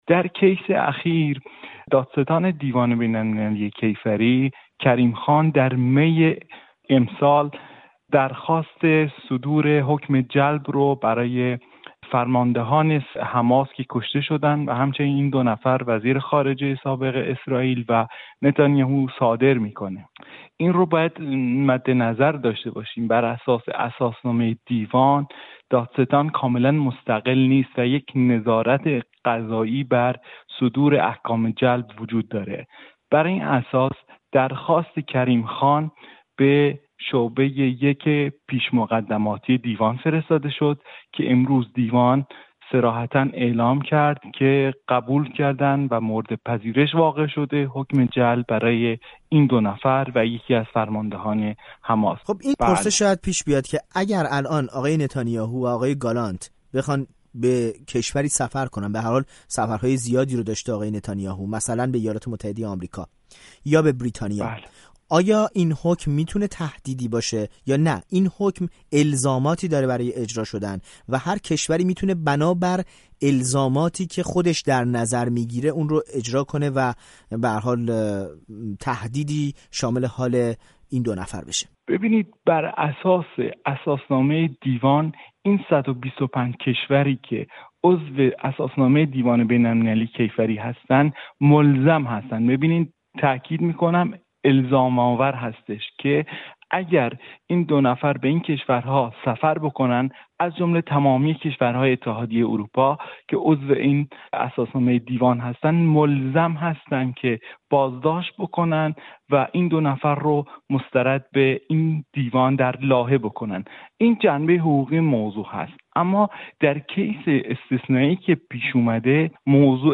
با برنامه خبری-تحلیلی ایستگاه۱۹ رادیو فردا در این زمینه گفت‌وگو کرده است.